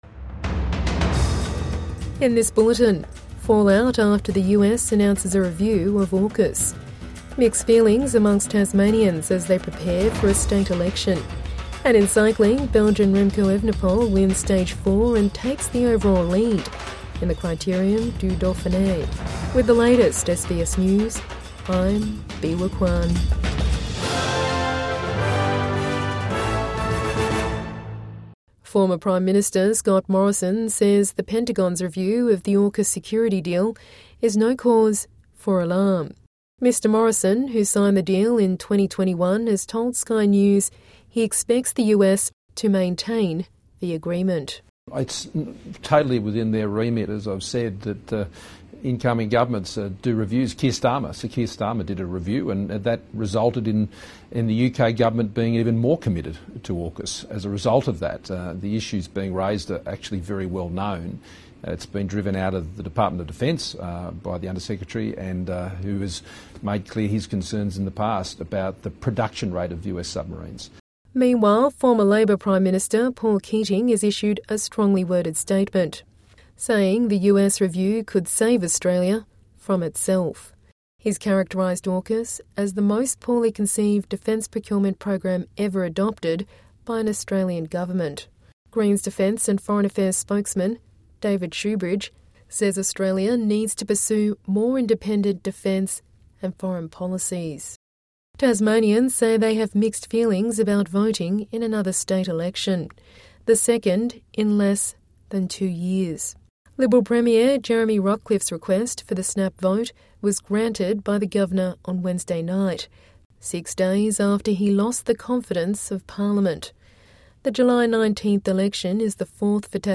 AUKUS review could save Australia from itself, former PM says| Evening News Bulletin 12 June 2025